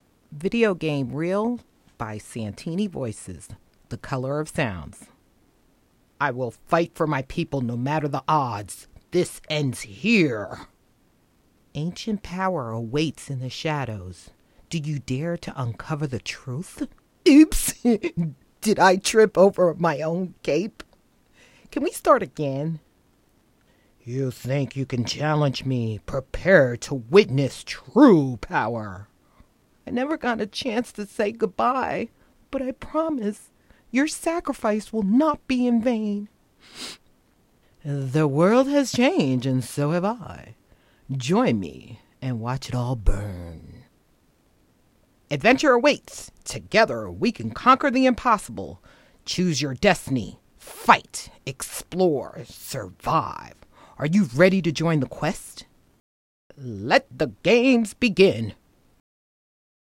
Video Game Reel
Video-Game-Reel.wav